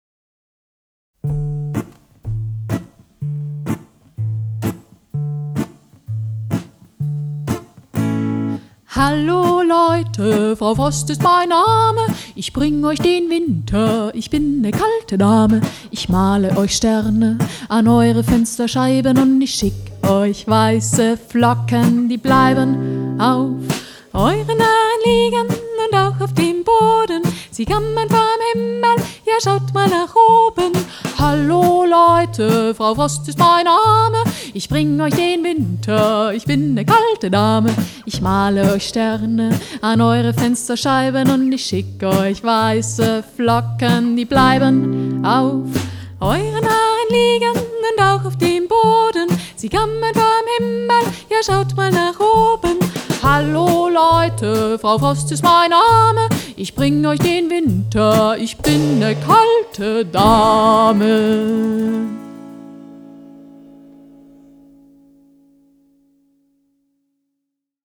Hörspiel
Das frostige Schlagzeug
Es singt und zupft